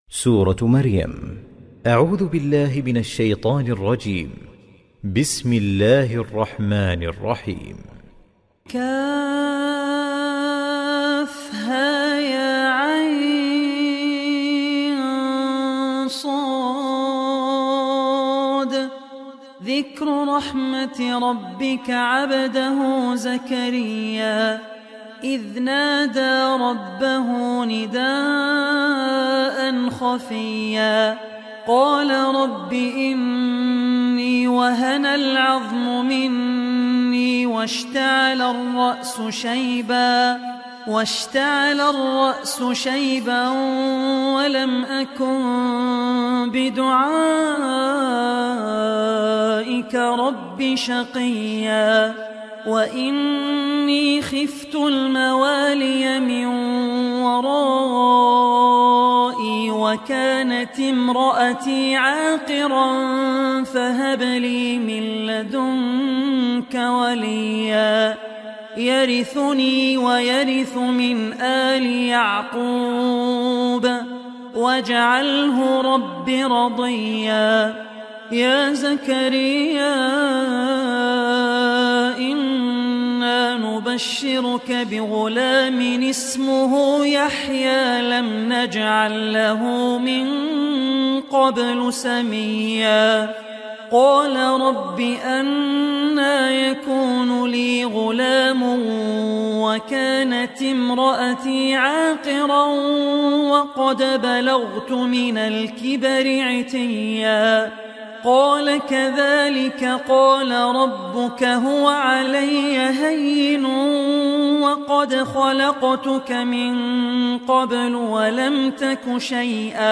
Surah Maryam Recitation by Abdur Rahman Al Ossi
Surah Maryam, listen online mp3 arabic recitation in the voice of Sheikh Abdur Rahman Al Ossi.